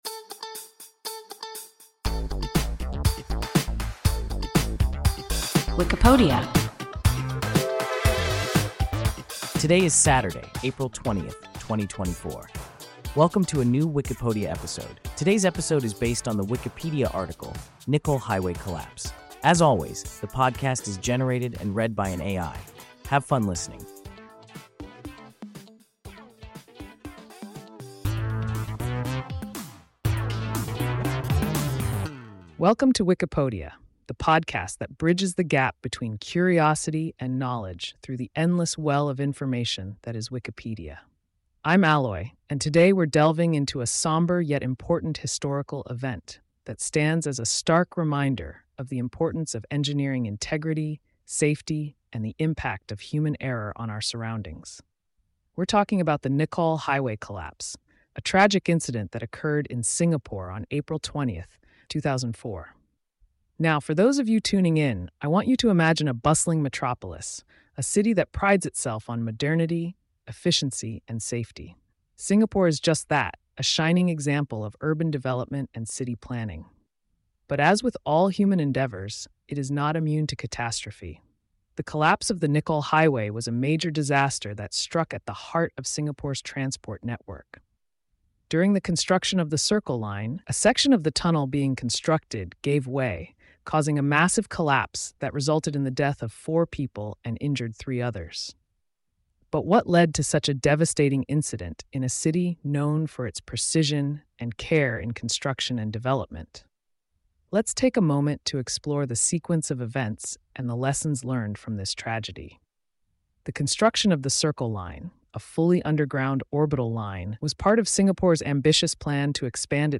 Nicoll Highway collapse – WIKIPODIA – ein KI Podcast